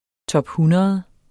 Udtale [ tʌbˈhunʌð ]